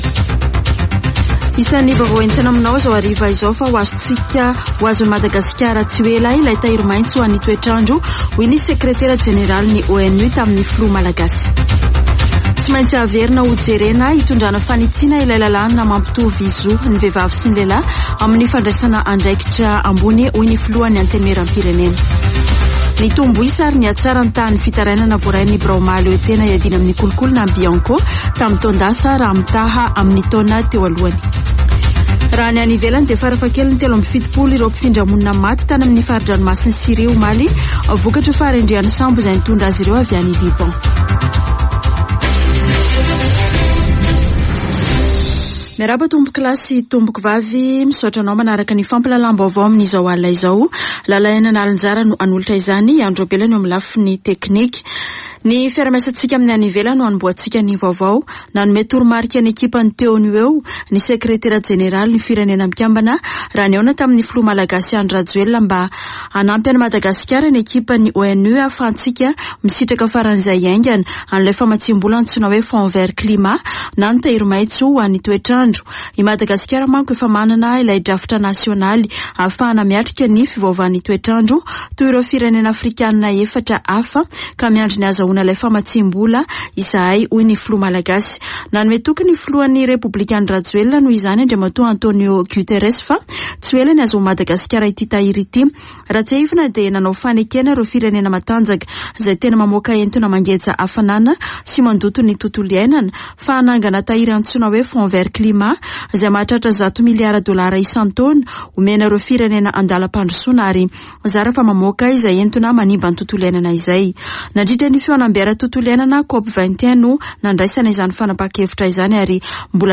[Vaovao hariva] Zoma 23 septambra 2022